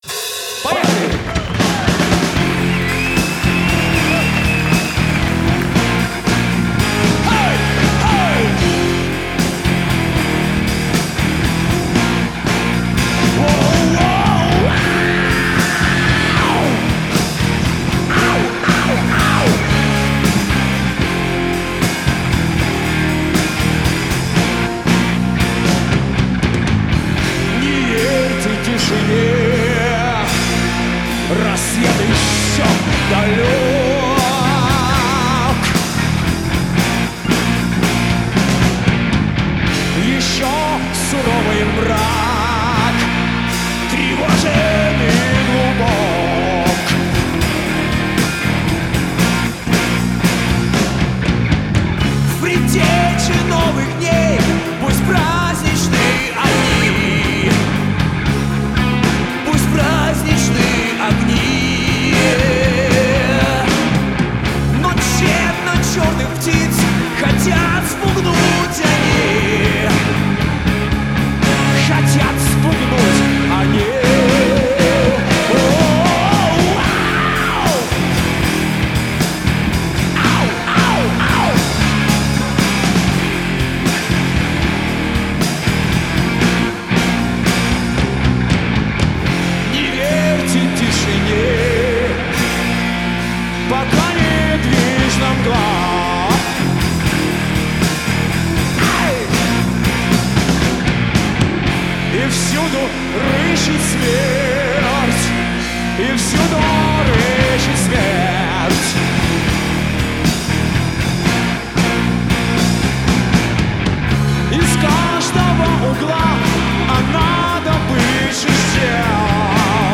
Жанр: Хард-рок, Рок, Soft Rock, Поп-рок
Живой концерт в Сергиевом Посаде